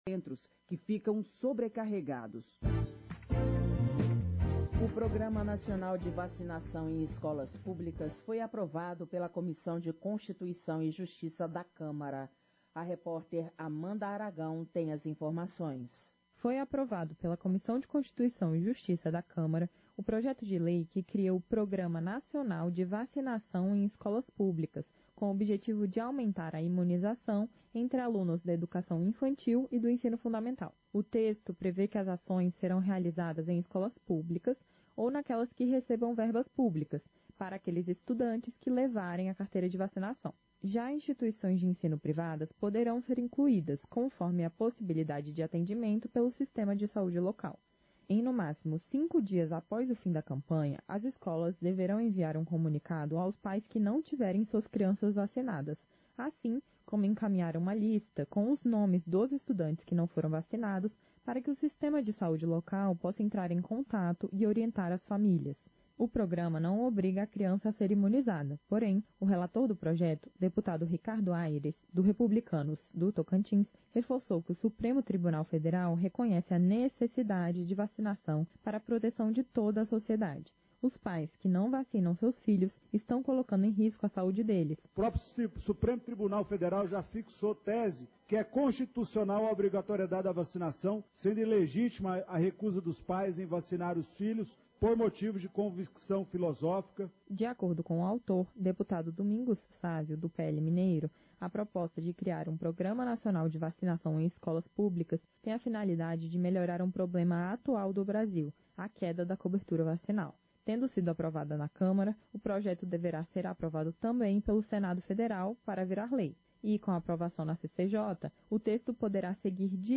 Sessão Ordinária 20/2023